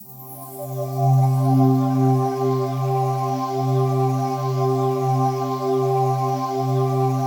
PAD 49-2.wav